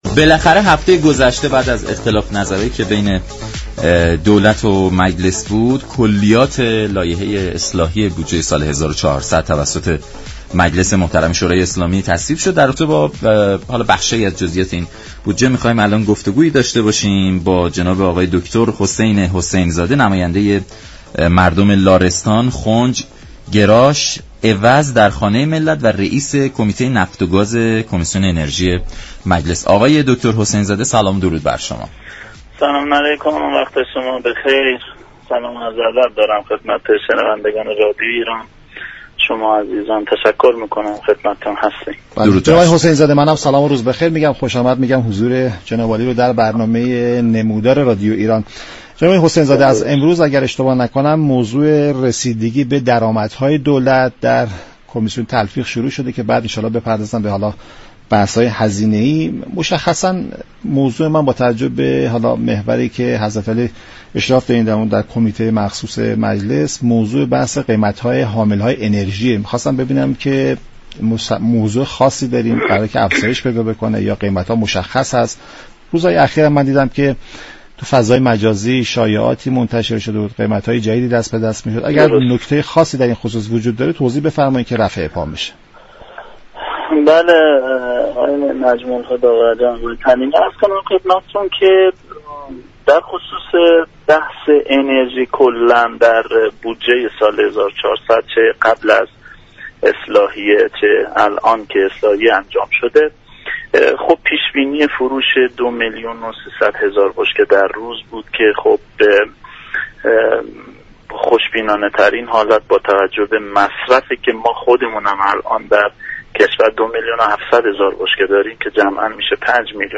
به گزارش شبكه رادیویی ایران، دكتر حسین حسین زاده رییس كمیته نفت و گاز كمیسیون انرژی مجلس در برنامه نمودار از تصویب كلیات اصلاحیه لایحه بودجه 1400 خبر داد و گفت: چه قبل و چه بعد از تصویب كلیات اصلاحیه بودجه 1400، پیش بینی فروش دو میلیون و 300 هزار بشكه در روز با توجه به مصرف كشور دور از واقعیات است.